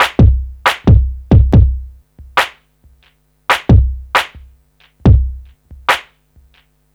C E.BEAT 1-R.wav